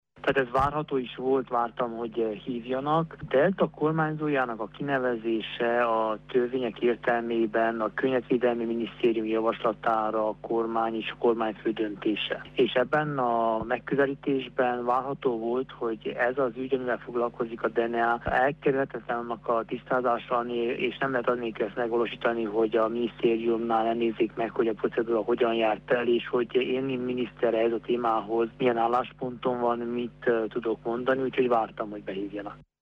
Korodi Attila nyilatkozott rádiónknak.